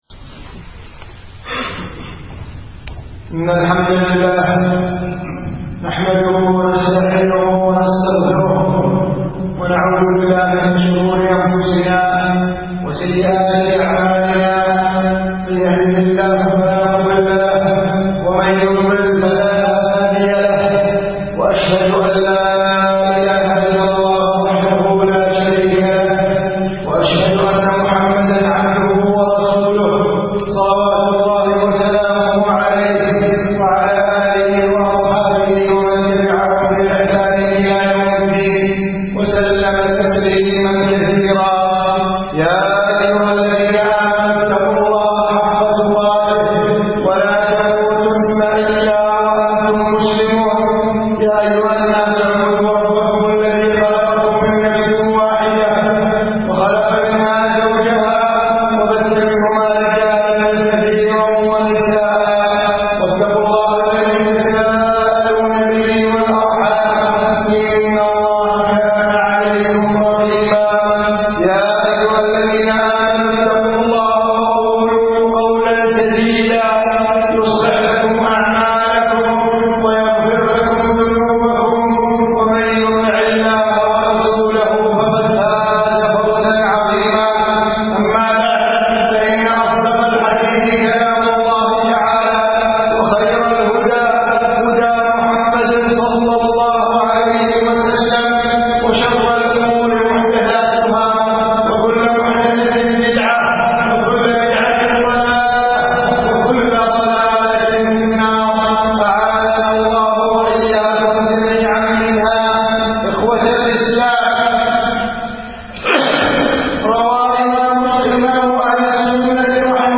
خطبة بتاريخ 11 محرم 1432